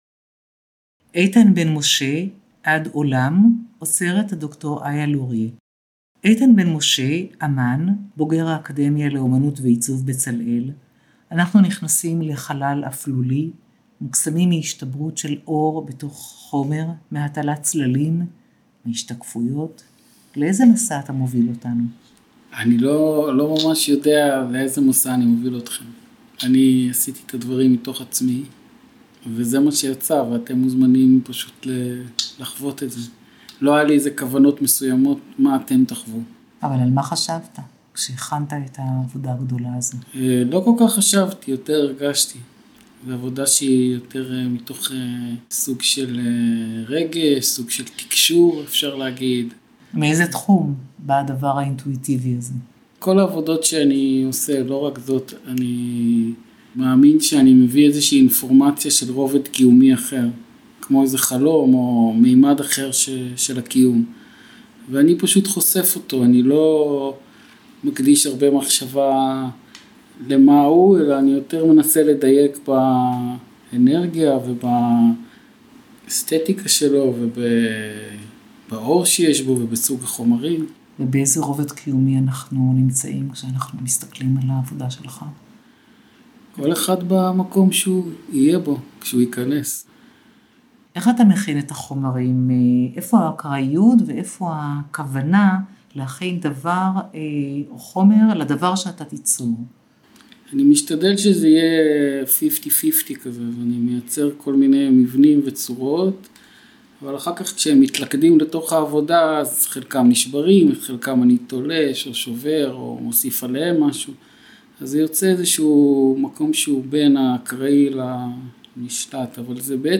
Skip Links אירוע הפתיחה קטלוג מדריך קולי תערוכות נוספות